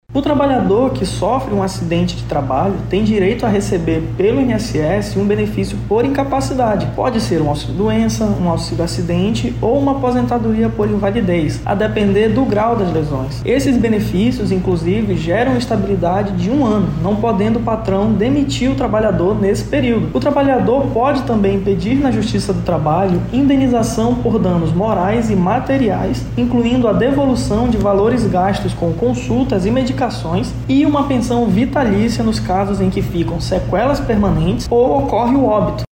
O advogado trabalhista e previdenciário